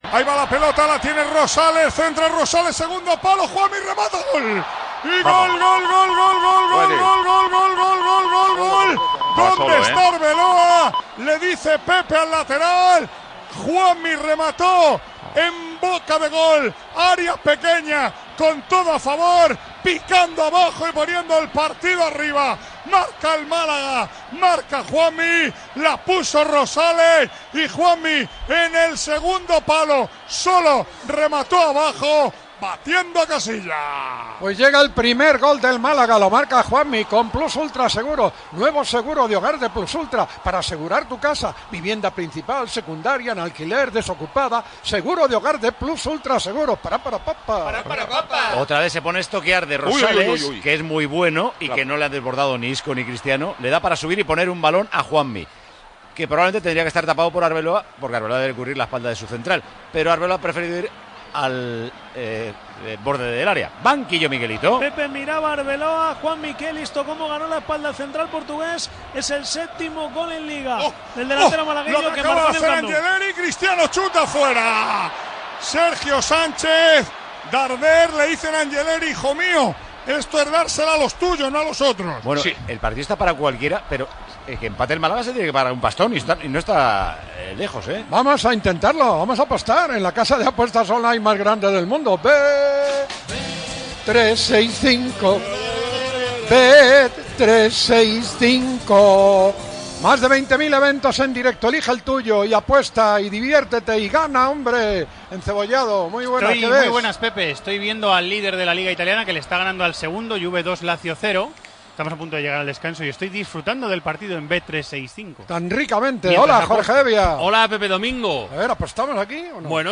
Transmissió del partit de la lliga de futbol masculí entre el Real Madrid i el Málaga.
Narració del gol de Juanmi per al Màlaga, publicitat, reacció de la banqueta, narració de la següent jugada i publicitat d'una casa d'apostes esportives.
Esportiu